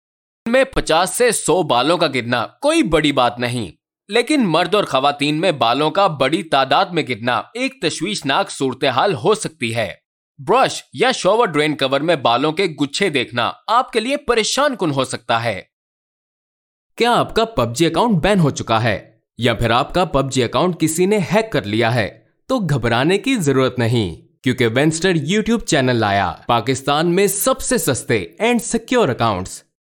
标签： 活力
配音风格： 大气 时尚 活力 稳重 亲切